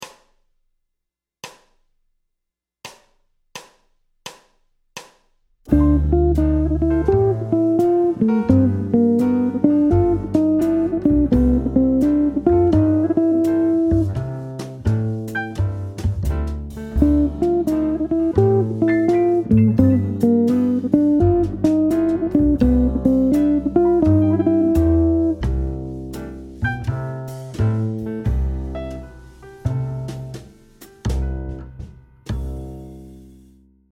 • 2 mesures de décompte
• 2 fois 4 mesures de solo
• jusqu’au fondu pour sortir
Phrase sur deux mesures d’un ‘ii. V7. I’ en C Majeur